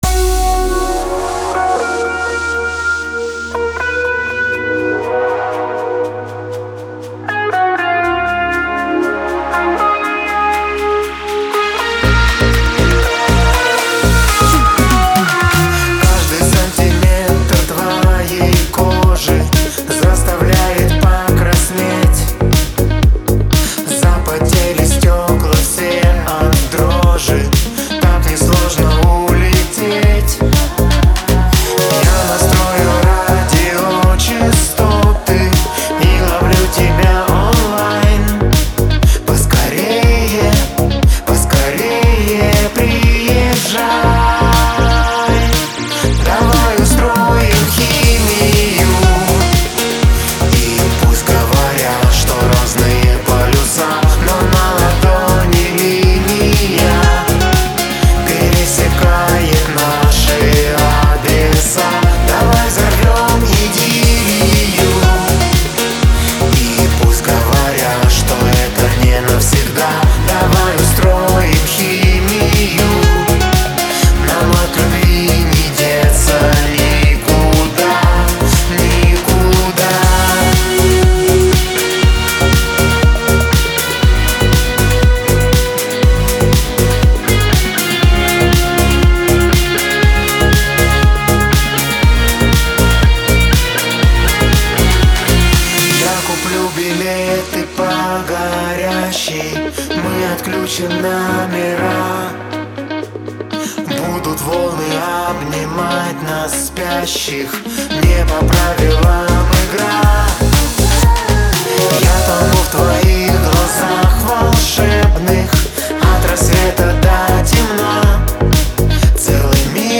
это энергичная и романтичная песня в жанре поп